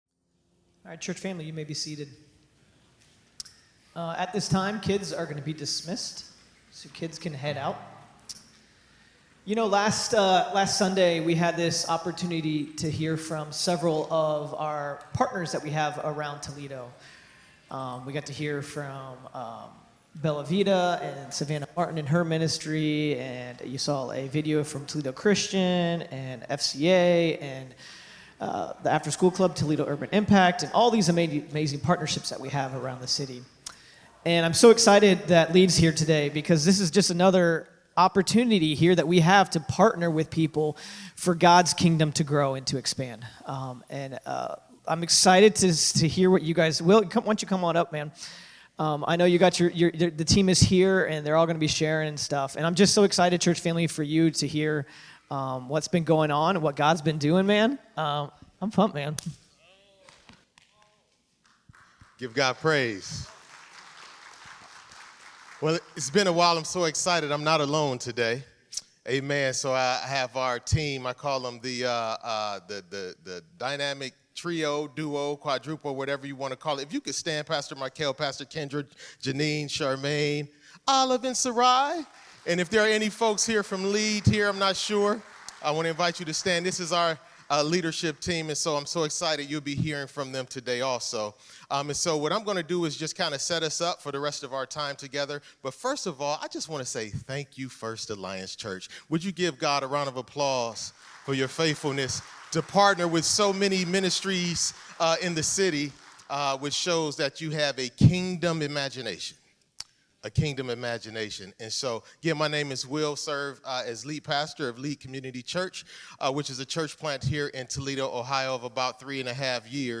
Sermons | First Alliance Church Toledo
Guest Speaker